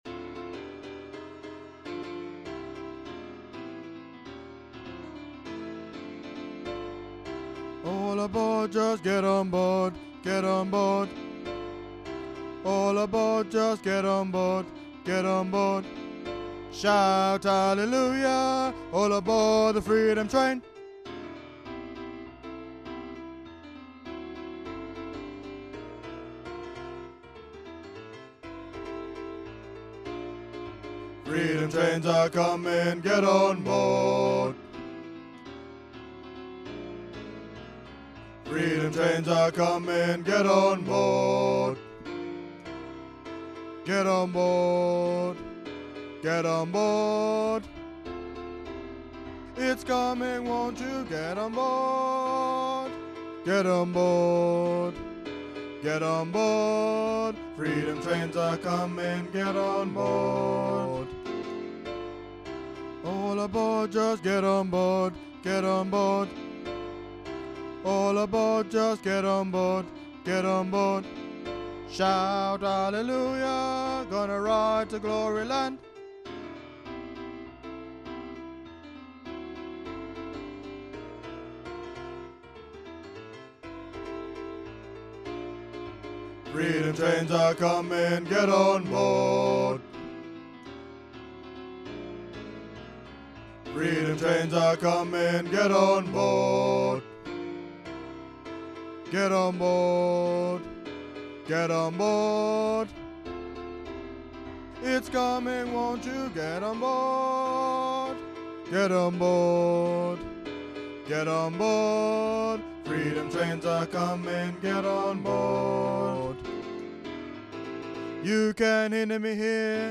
FREEDOM TRAIN BY ROLLO DILLWORTH - for educational learning purposes only! Enjoy singing using transcription of lyrics below: